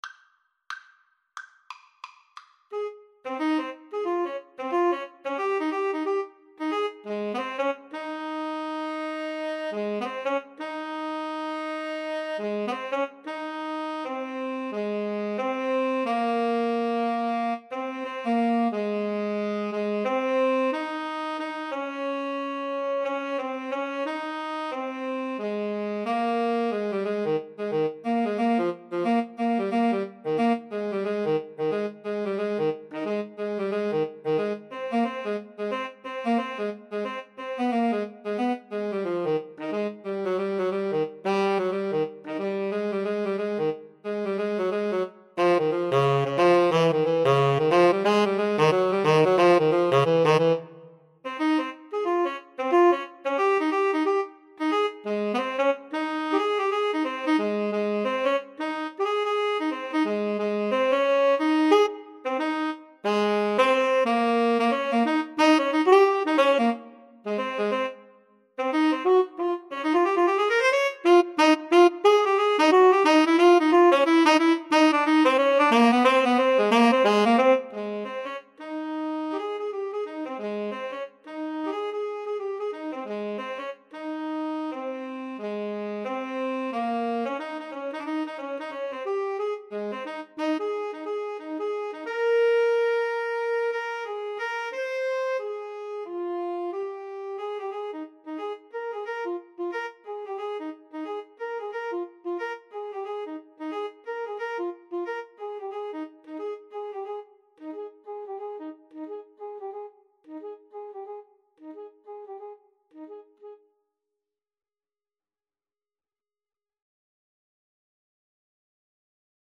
Fast and with a swing =c.90